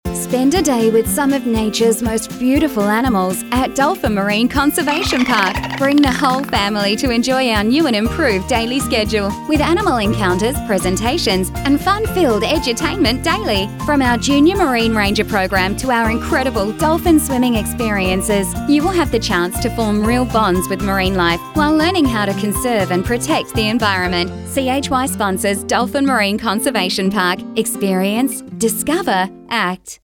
Radio Commercial